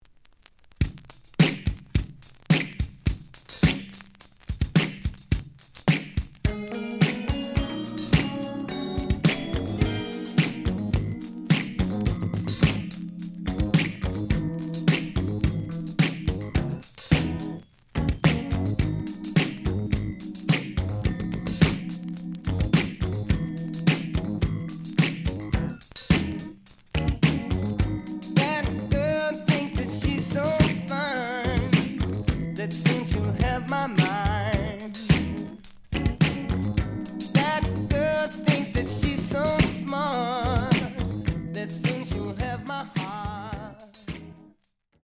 The audio sample is recorded from the actual item.
●Format: 7 inch
●Genre: Soul, 80's / 90's Soul
Slight edge warp.